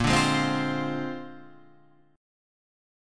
A#9 chord {6 8 6 7 6 8} chord